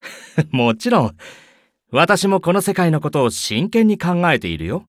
文件 文件历史 文件用途 全域文件用途 Ja_Bhan_amb_02.ogg （Ogg Vorbis声音文件，长度4.9秒，105 kbps，文件大小：63 KB） 源地址:游戏语音 文件历史 点击某个日期/时间查看对应时刻的文件。 日期/时间 缩略图 大小 用户 备注 当前 2018年5月25日 (五) 02:58 4.9秒 （63 KB） 地下城与勇士  （ 留言 | 贡献 ） 分类:巴恩·巴休特 分类:地下城与勇士 源地址:游戏语音 您不可以覆盖此文件。